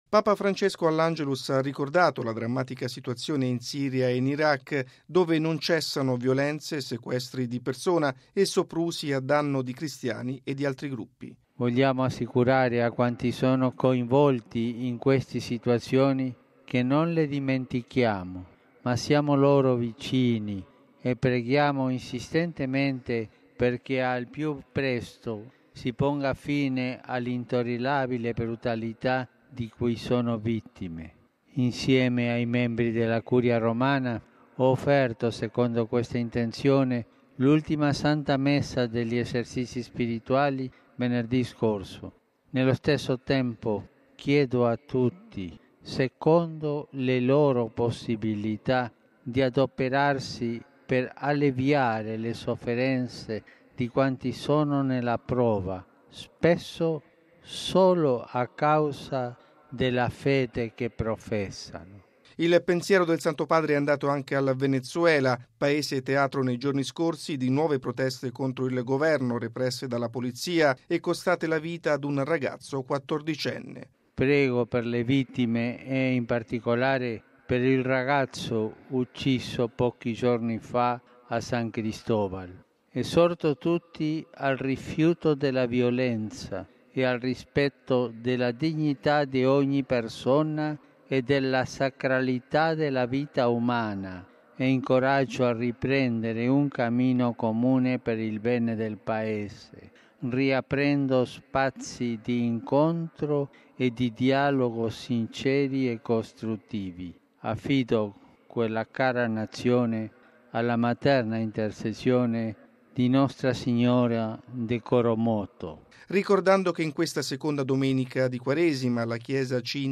Bollettino Radiogiornale del 01/03/2015